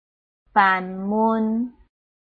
臺灣客語拼音學習網-客語聽讀拼-詔安腔-鼻尾韻
拼音查詢：【詔安腔】mun ~請點選不同聲調拼音聽聽看!(例字漢字部分屬參考性質)